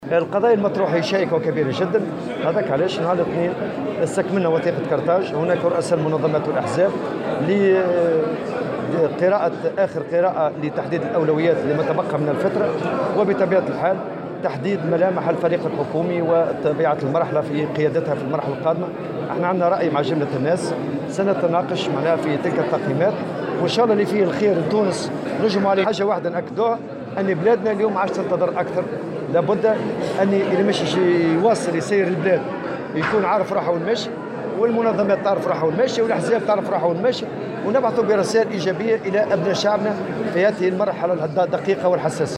وأضاف الطبوبي في تصريح لمراسل "الجوهرة أف أم" على هامش افتتاح مؤتمر الاتحاد الجهوي للشغل بالمهدية، أنه تم الانتهاء من صياغة وثيقة قرطاج 2، وأنه سيتم بالتعاون مع رؤساء المنظمات الوطنية والأحزاب مناقشة الأولويات لما تبقى من المرحلة وتحديد ملامح الفريق الحكومي.